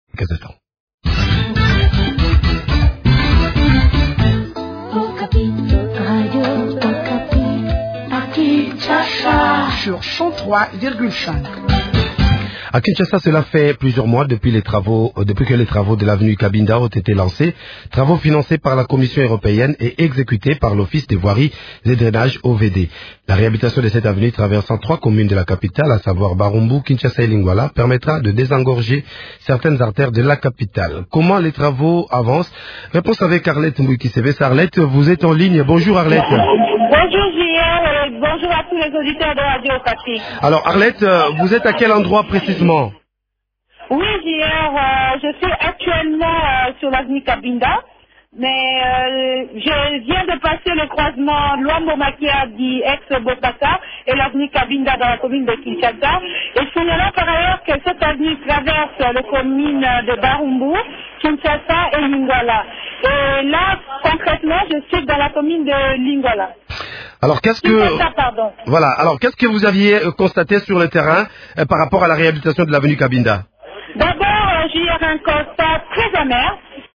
en parle avec